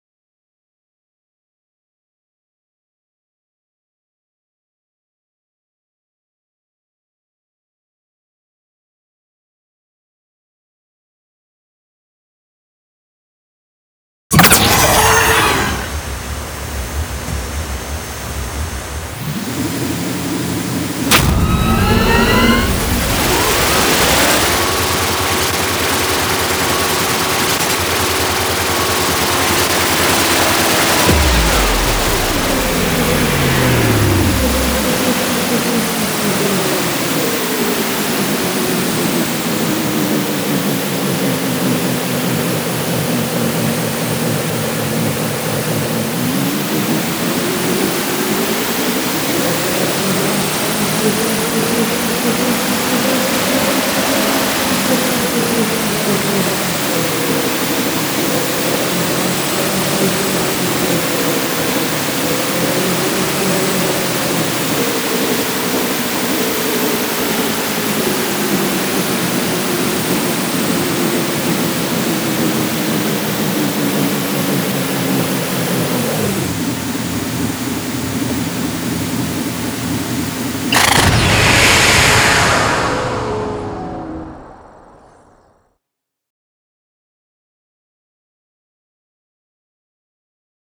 The system will simulate a ride and record the dynamic spike sound using the provided preset. Throttle values and boost times are randomised, so to fully understand a preset, multiple recordings might be necessary.